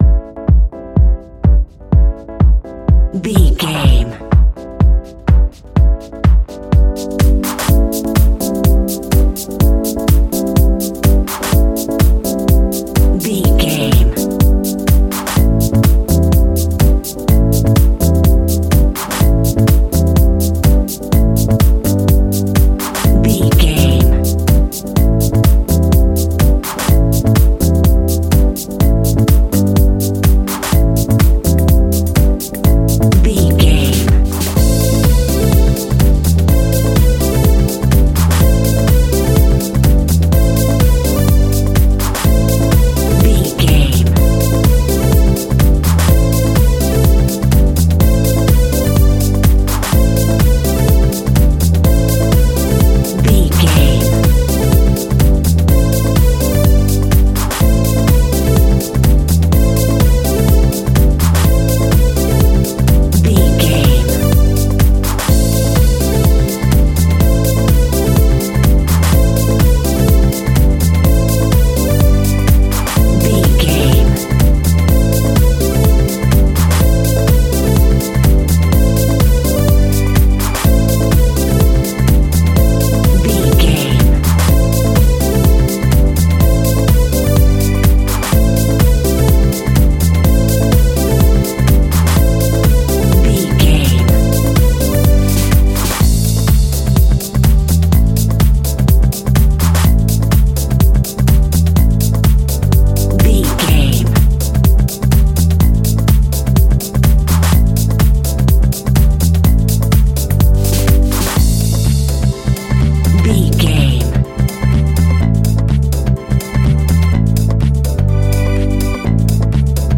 Aeolian/Minor
groovy
uplifting
energetic
electric piano
bass guitar
strings
drums
funky house
disco house
electro funk
clavinet
horns